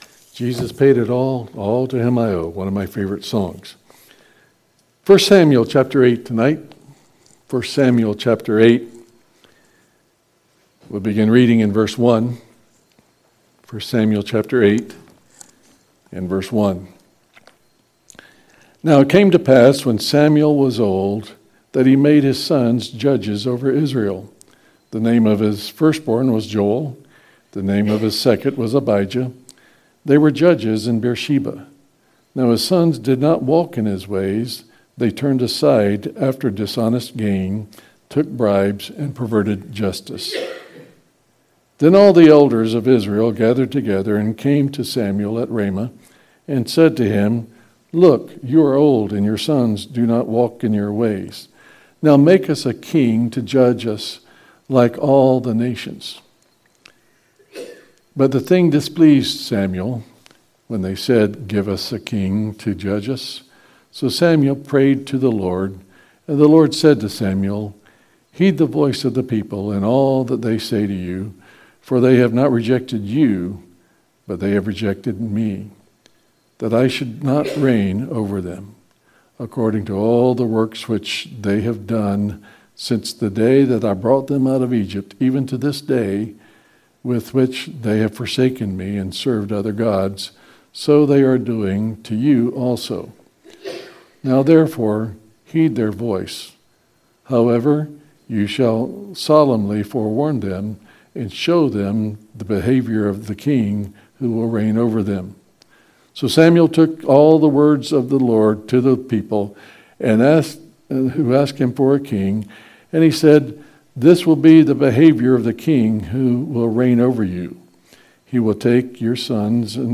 No Darkness At All | SermonAudio Broadcaster is Live View the Live Stream Share this sermon Disabled by adblocker Copy URL Copied!